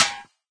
icemetal.ogg